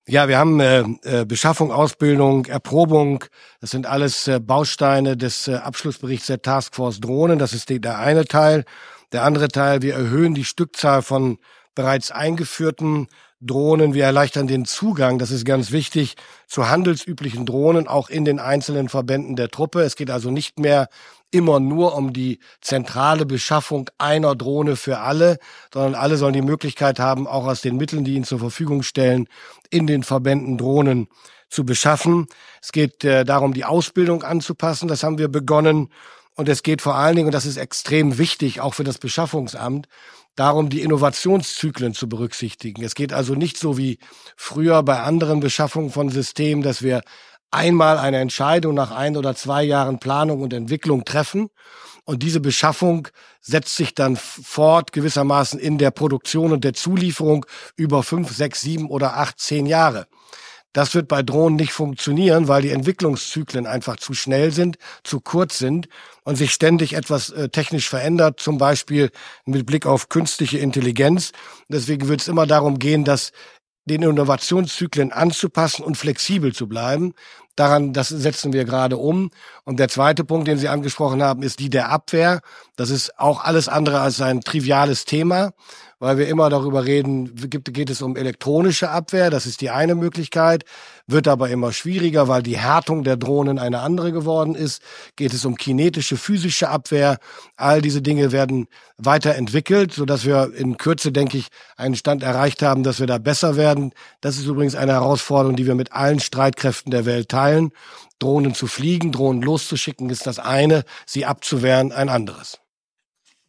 Wer die Pressekonferenz von Verteidigungsminister Boris Pistorius bei seinem Besuch im Bundesamt für Ausrüstung, Informationstechnik und Nutzung der Bundeswehr (BAAINBw) am (gestrigen) Donnerstag verfolgte, dürfte auch diese etwas kryptische Passage zum Thema Drohnen mitbekommen haben: